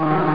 WASP_FLY.mp3